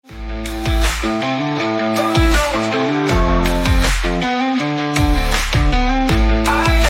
Genre: Pop Rock
Performance: AI Band & Vocals (High-End AI )